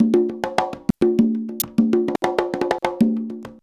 133CONGA06-R.wav